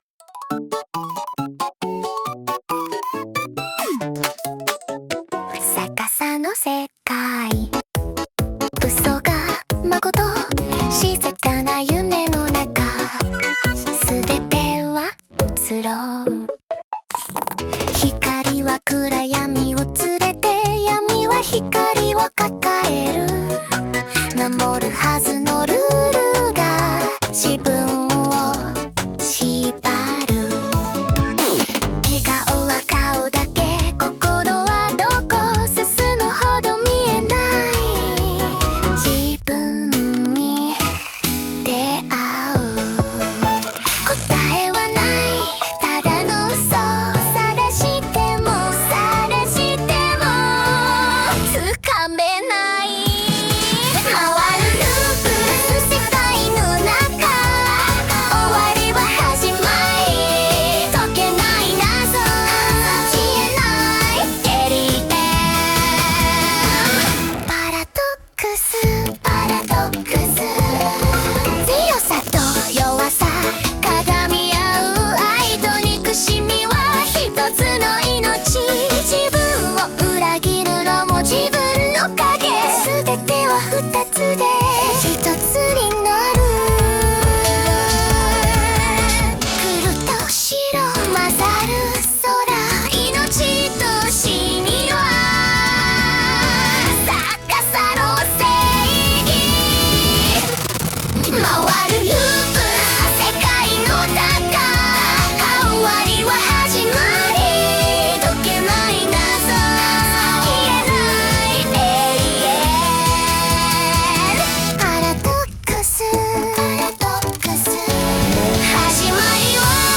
女性ボーカル
イメージ：女性ボーカル,J-POPサーカス,シンセサイザー,逆さの世界,白と黒